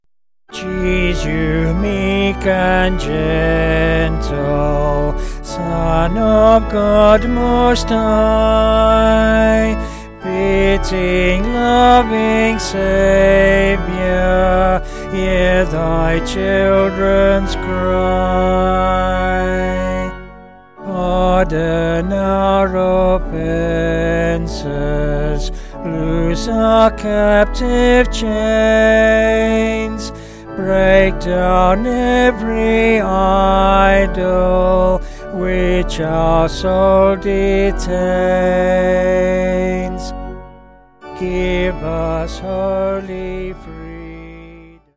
Vocals and Organ